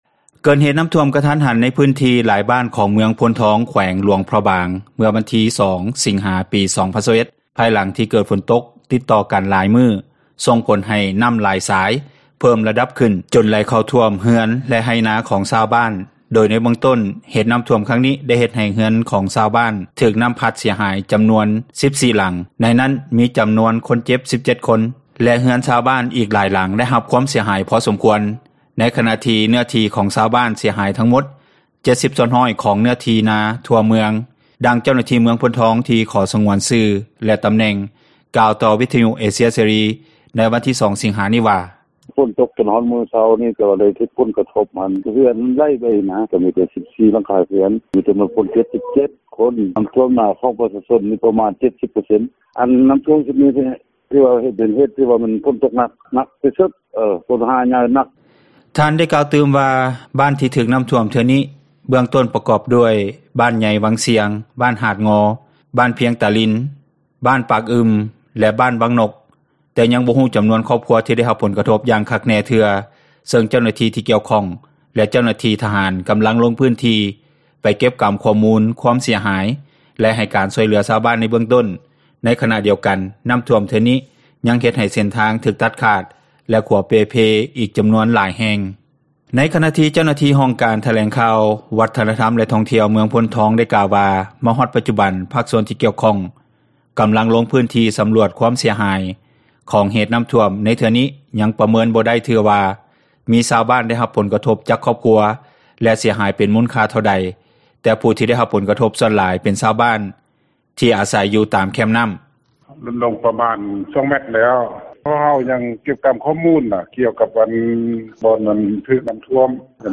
ມ.ໂພນທອງ ຂ.ຫຼວງພຣະບາງ ຖືກນໍ້າຖ້ວມ – ຂ່າວລາວ ວິທຍຸເອເຊັຽເສຣີ ພາສາລາວ